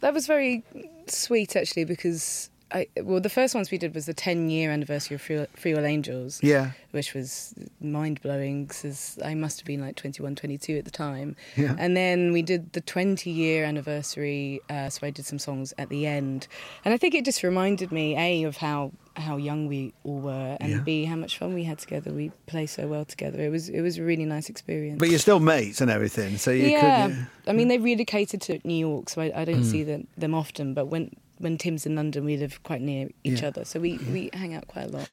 Charlotte Hatherley chats to Radcliffe & Maconie about Ash.